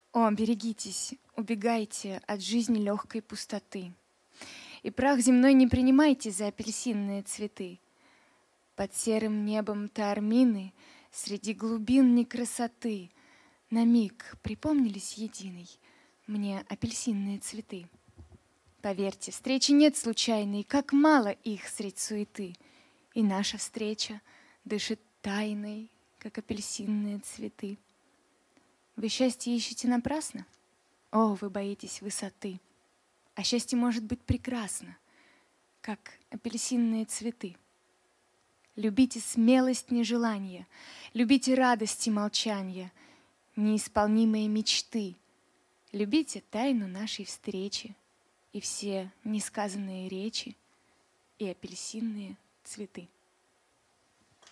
Песни на стихотворение: